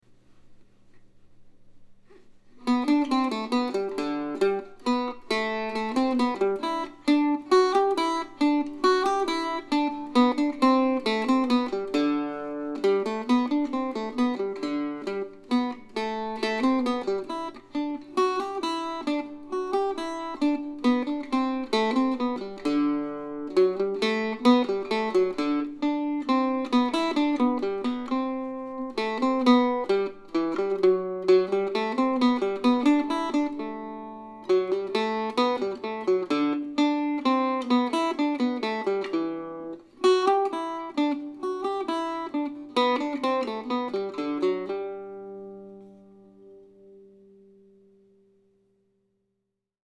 Summer Suite, August, 2020 (for Octave Mandolin or Mandocello)
I added short introductions to a couple of the pieces, I changed keys a couple of times and I added drones here and there.